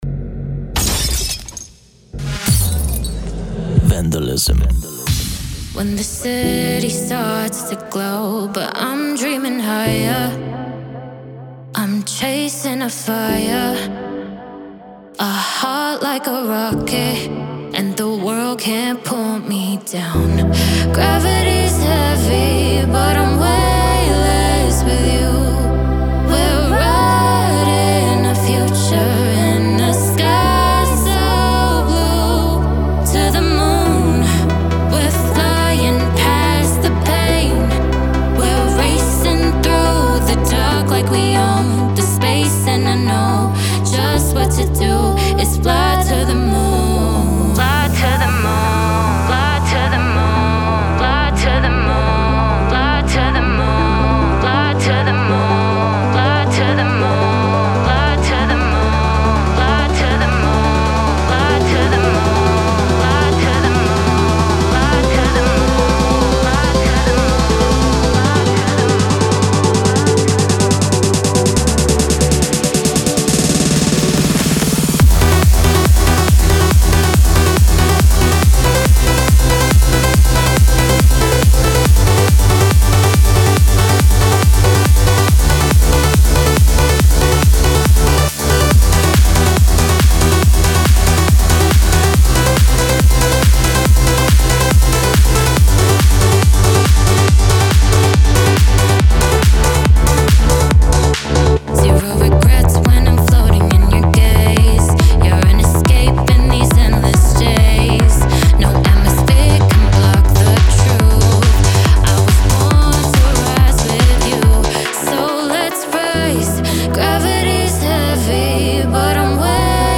Genre: Trance Uplifting Trance Vocals